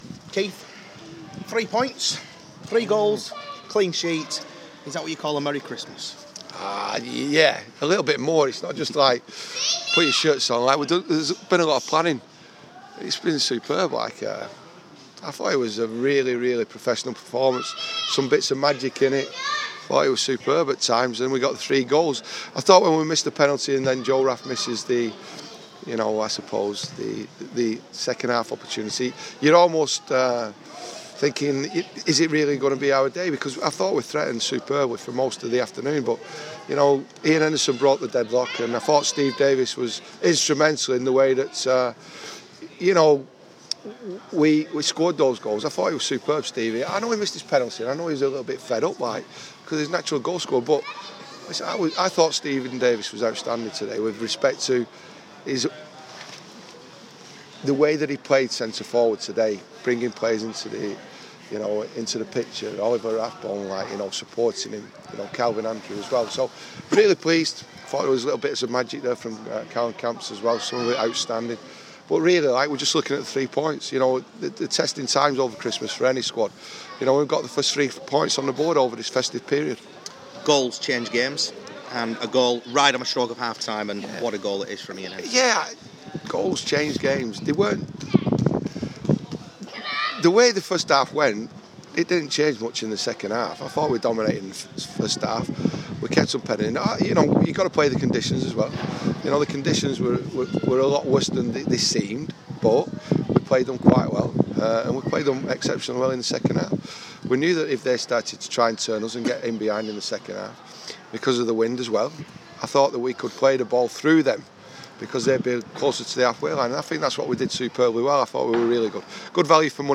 Rochdale manager Keith Hill shares his thoughts after a convincing 3-0 win over Chesterfield.